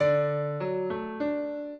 piano
minuet15-6.wav